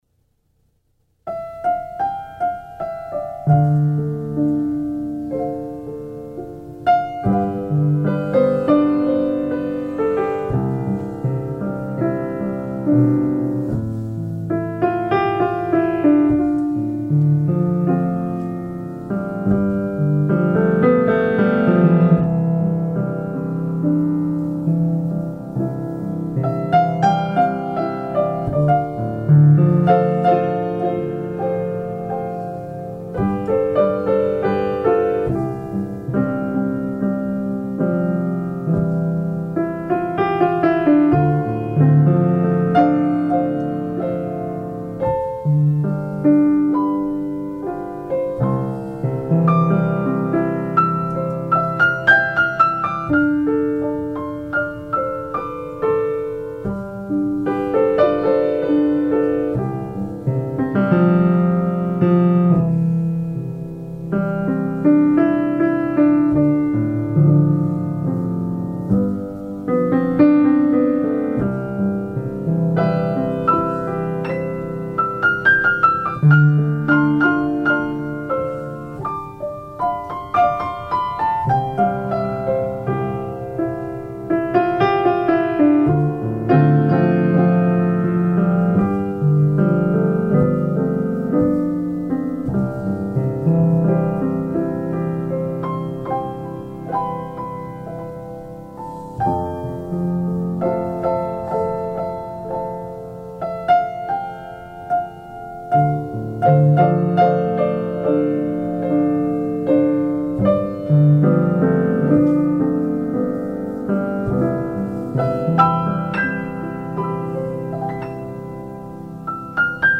a gentle, calming peace to restore your health and hope
In the 528 Hz tone for miracles!